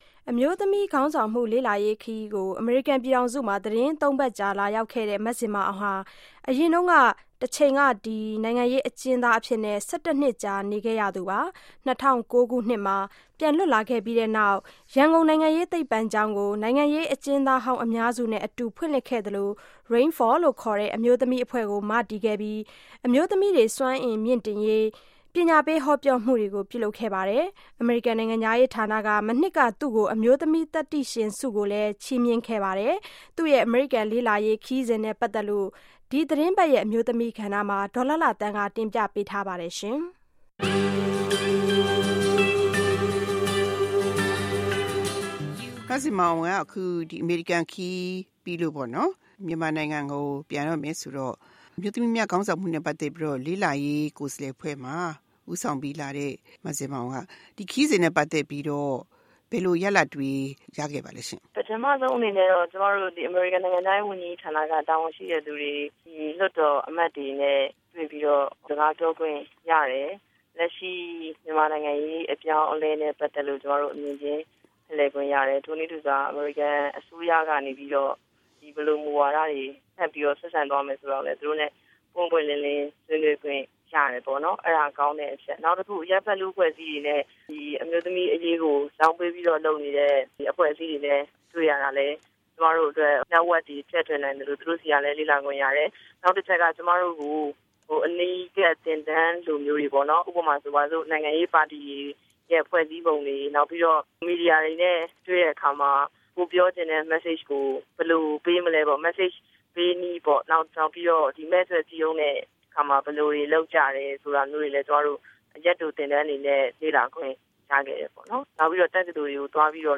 မဇင်မာအောင်နဲ့ မေးမြန်းချက်